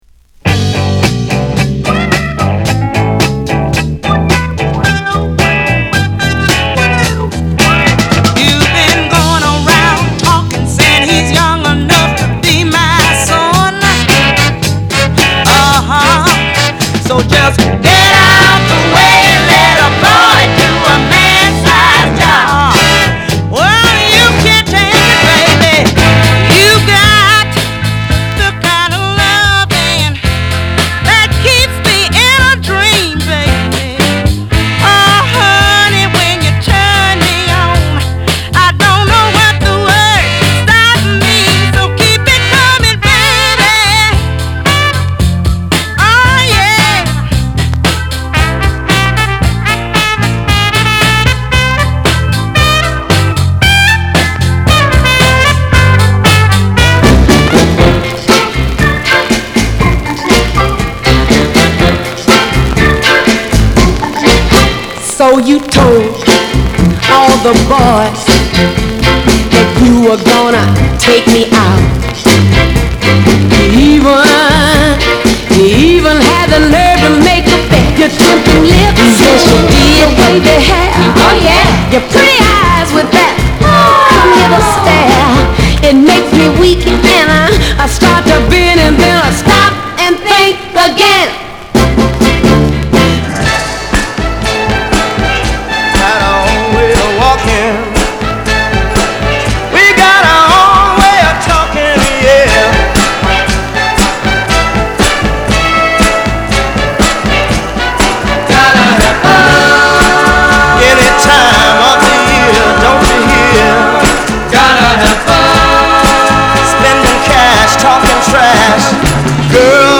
R&B、ソウル
/盤質/両面 やや傷あり再生良好/US PRESS